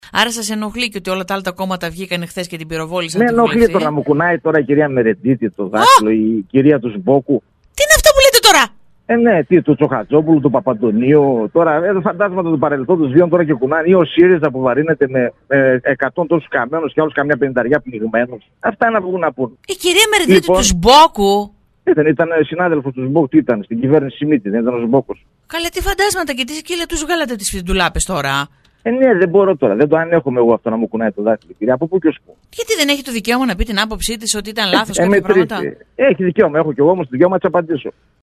για λεγόμενα του σε συνέντευξη στη Ραδιοφωνική Λέσχη 97,6.